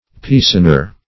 Search Result for " piecener" : The Collaborative International Dictionary of English v.0.48: Piecener \Piece"ner\, n. 1.